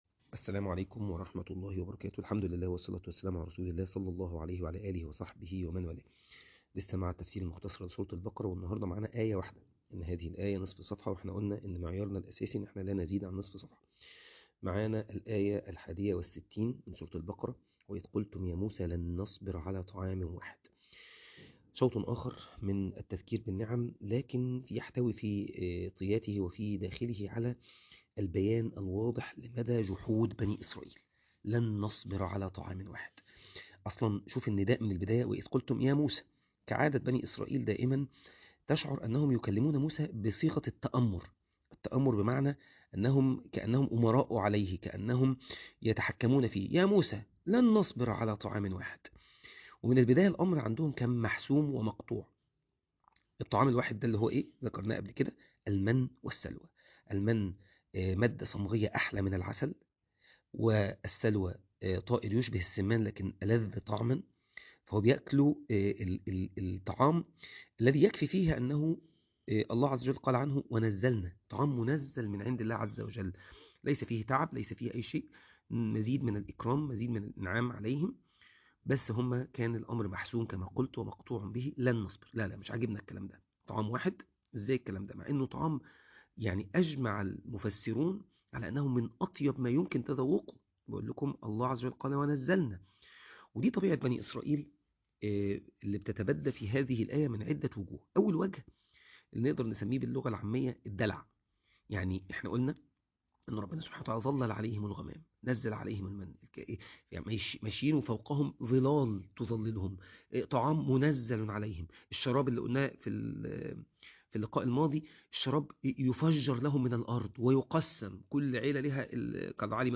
التلاوة